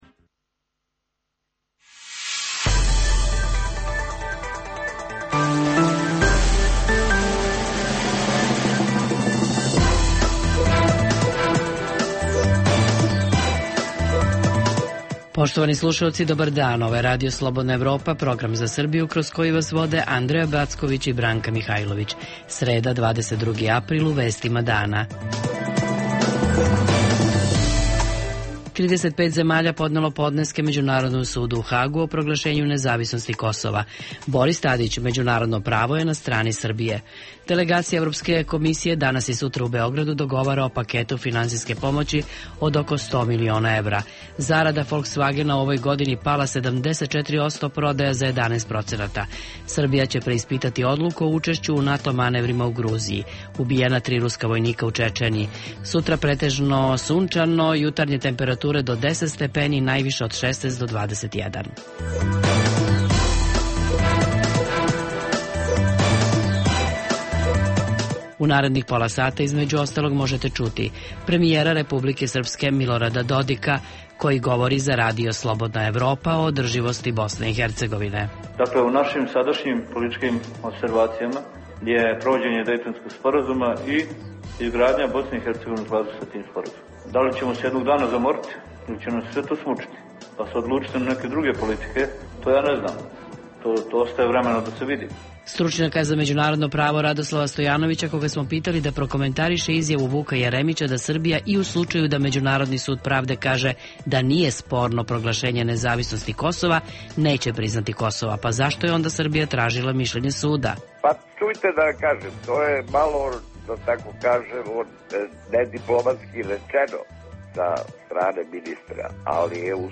U emisiji objavljujemo intervju sa Miloradom Dodikom, koji odgovara na pitanje naše novinarke o tome koliko Bosnu i Hercegovinu Srbi doživljavaju kao svoju, da li Banja Luka računa i dalje na trgovinu Kosovo za Republiku Srpsku, da li je na dnevnoj vezi sa Borisom Tadićem? Govorimo o tome da li rejting Hrvatske u Srbiji raste kao što raste Srbije u Hrvatskoj (ljube li Srbi Hrvate kao što oni ljube njih), te kako su mediji izveštavali o bombardovanju državne TV '99. godine.